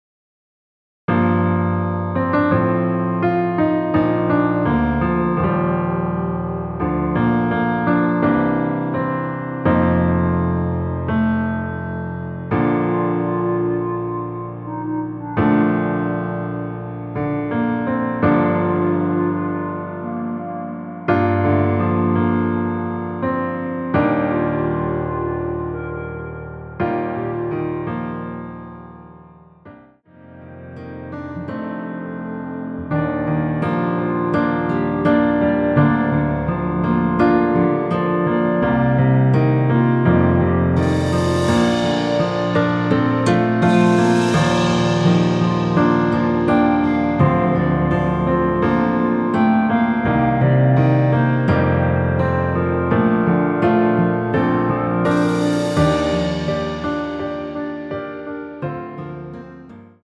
원키에서(-1)내린 멜로디 포함된 MR입니다.
Bb
앞부분30초, 뒷부분30초씩 편집해서 올려 드리고 있습니다.
중간에 음이 끈어지고 다시 나오는 이유는